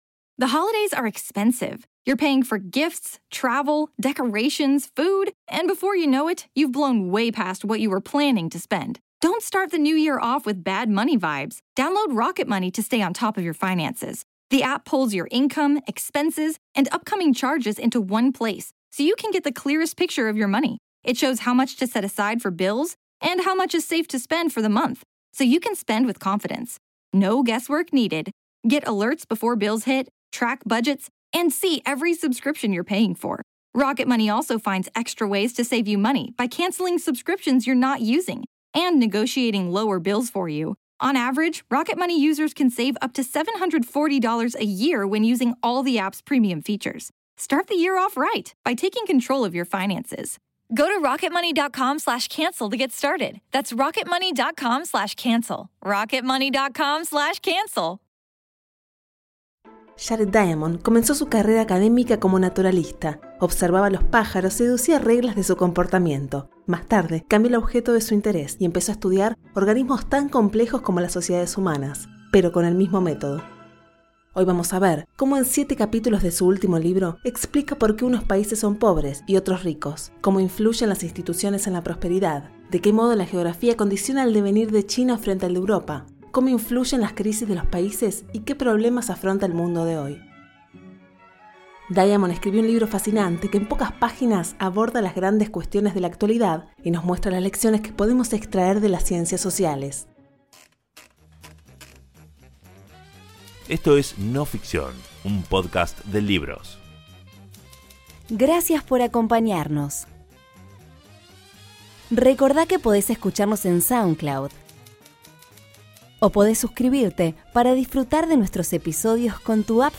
Narradora